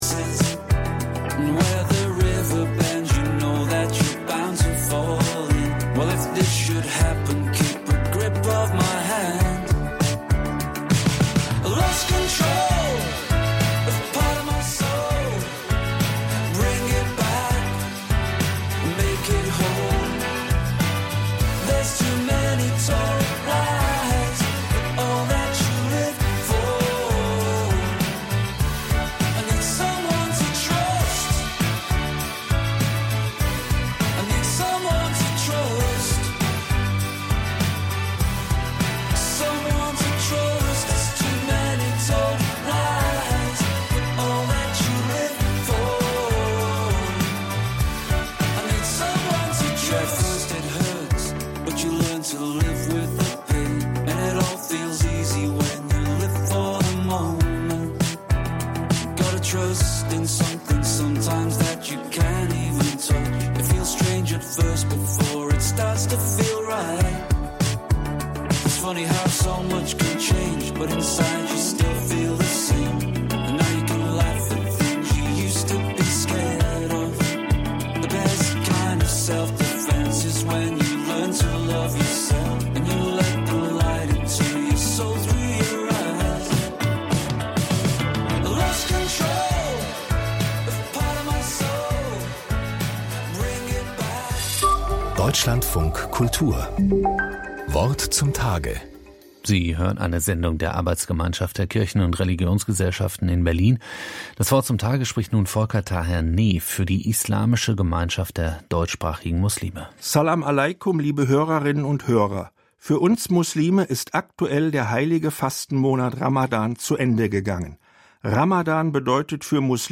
وی در این سخنرانی کوتاه به فلسفه روزه گرفتن در ماه مبارک رمضان اشاره داشت.